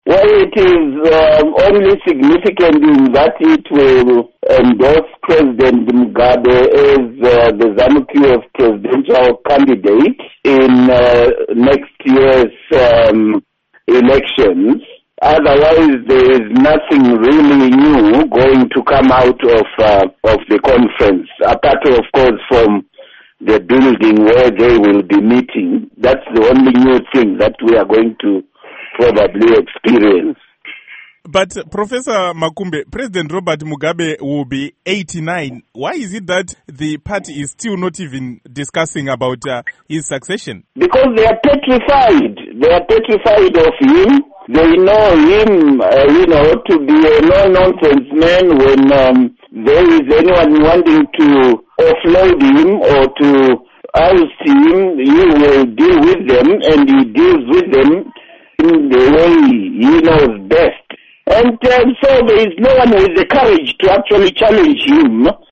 Interview With John Makumbe